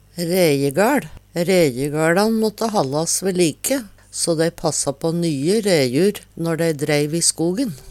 Sjå òg reje (Veggli) rejehæsj (Veggli) Høyr på uttala